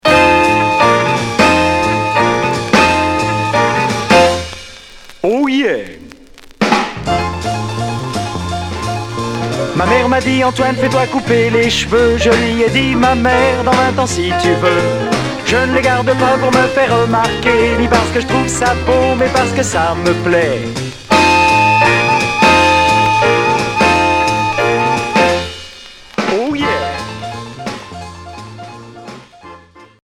Pop folk beat